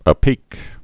(ə-pēk)